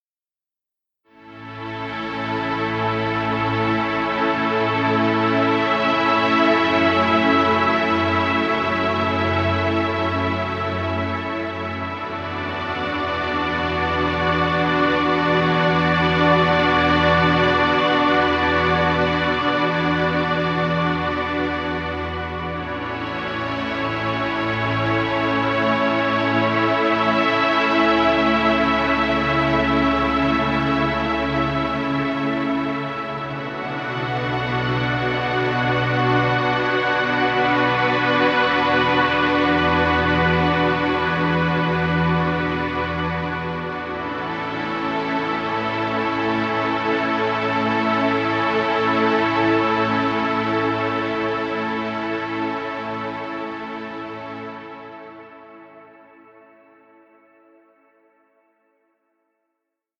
Relaxing music. Background music Royalty Free.
Stock Music.